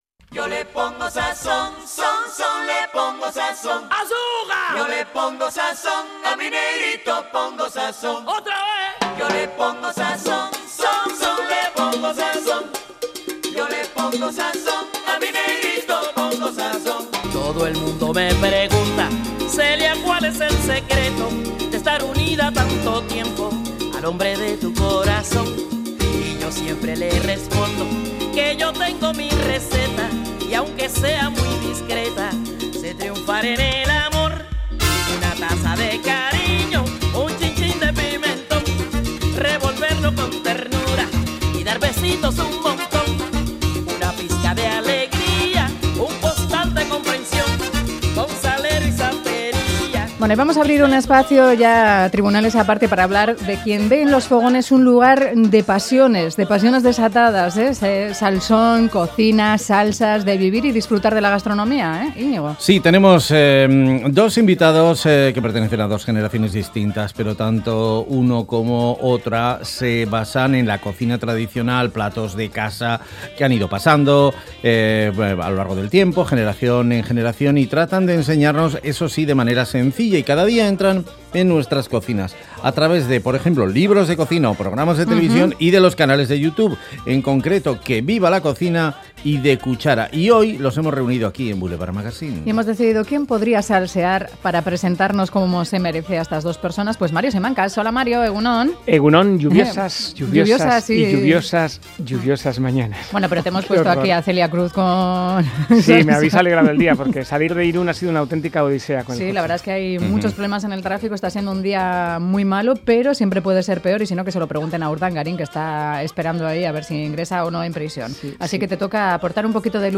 Audio: Reunimos en Boulevard Magazine a dos cocineros de distintas generaciones con una pasión común:difundir los platos tradicionales de toda la vida a través de sus libros y de Youtube...